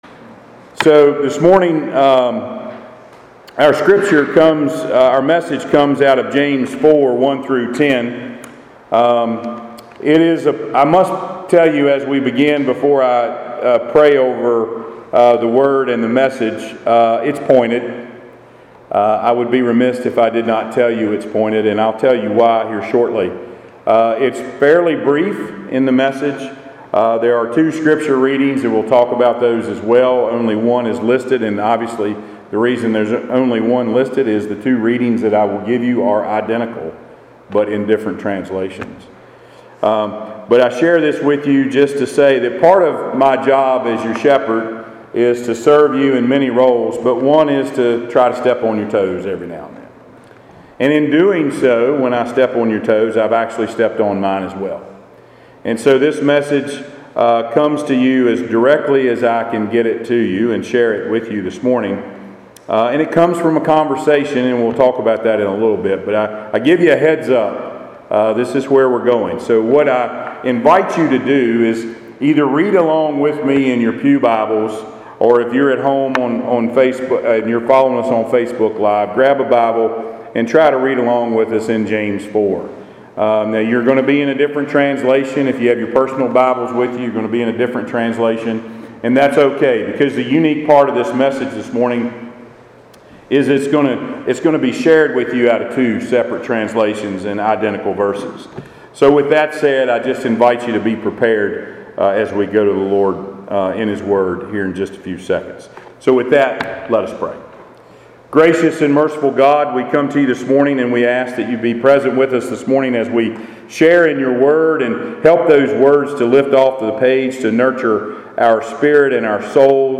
Passage: James 4:1-10 Service Type: Sunday Worship